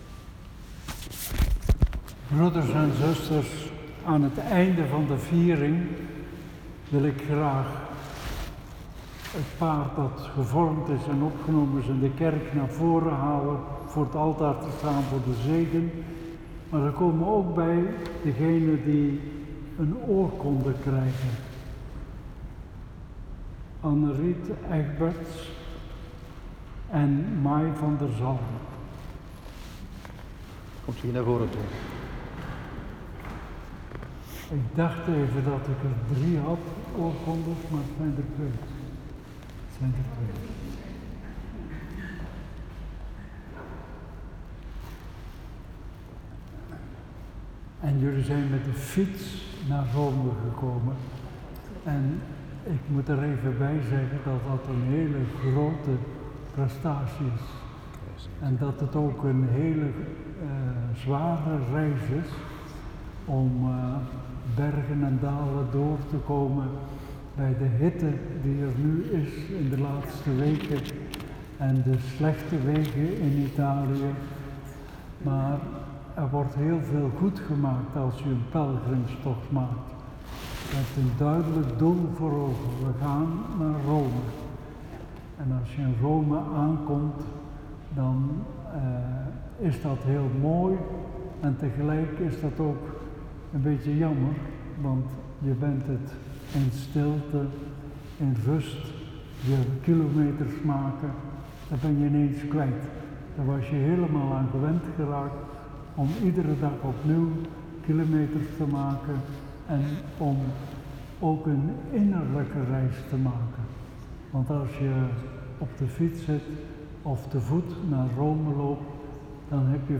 Liturgie; Liturgie Eucharistieviering; Celebranten, vrijwilligers en gasten; Lezingen, Evangelie; Voorbeden; Inleidend woord, preek en slotwoord door Mons. Hurkmans.
Viering 21 juli 2019
Slotwoord-3.m4a